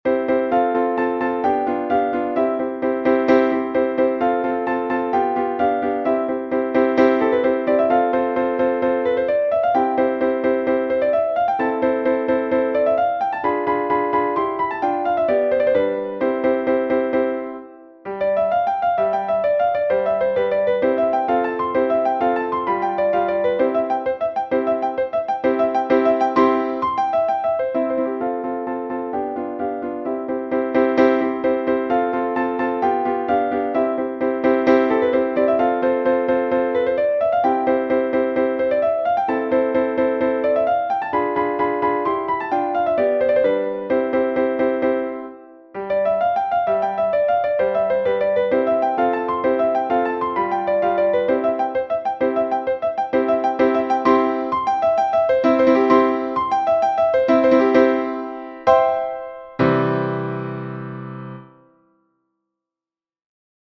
Piano movement 183 - Piano Music, Solo Keyboard